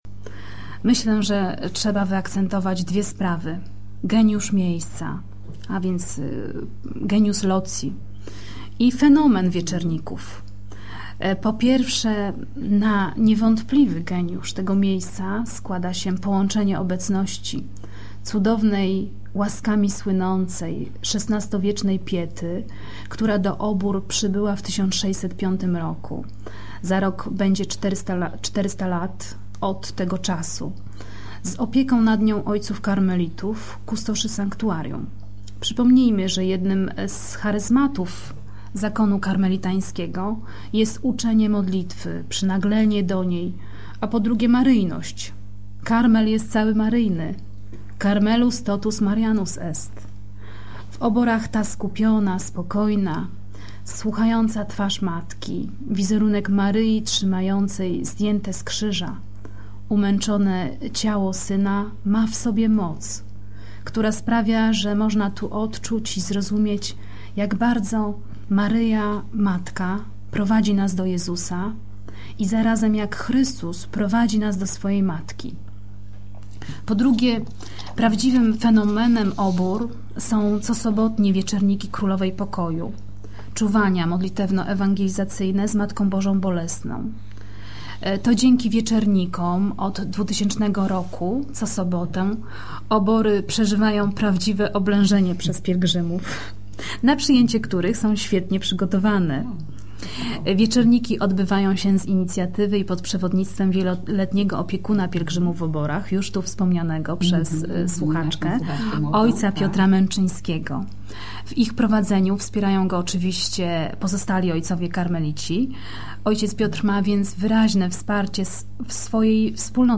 Wywiad radiowy – Radio Głos 18.08.2004 cz. 2